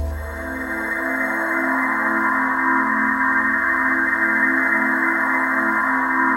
ATMOPAD03.wav